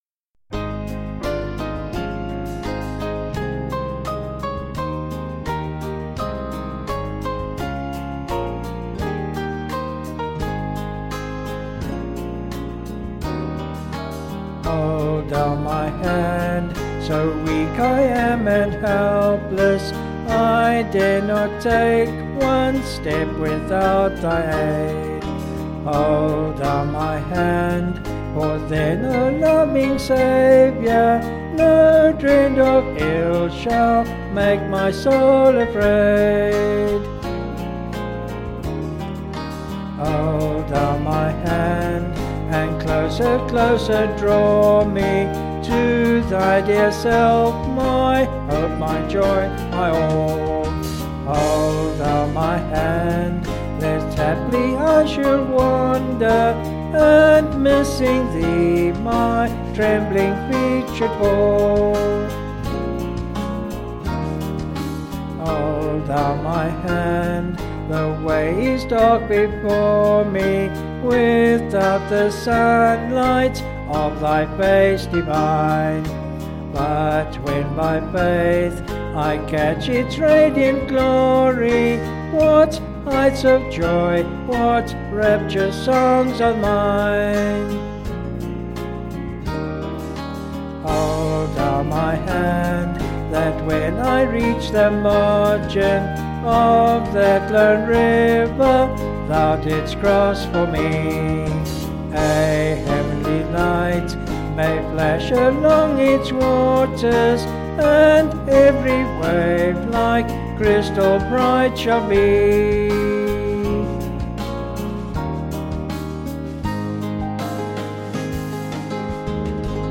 (BH)   4/Ab
Vocals and Band   264.1kb Sung Lyrics